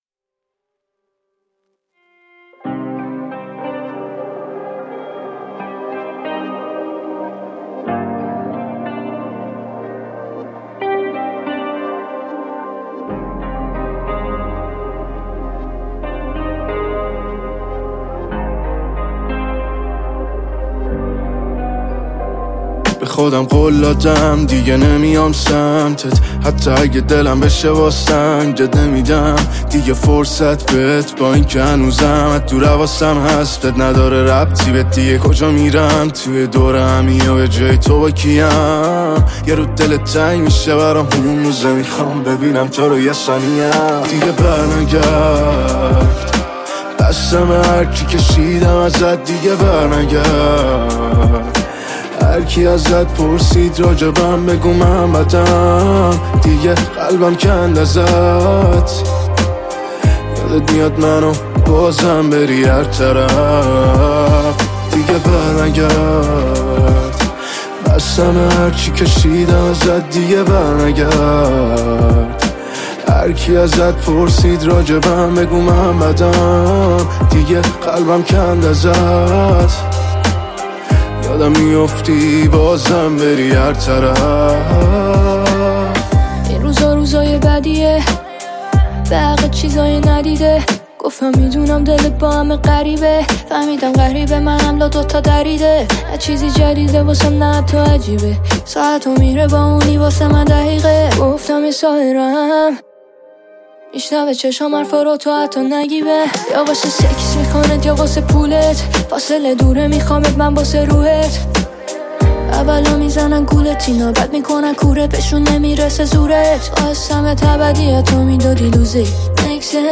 موزیک،پاپ